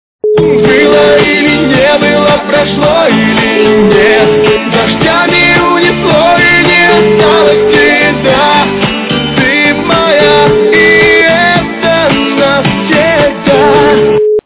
- русская эстрада
качество понижено и присутствуют гудки.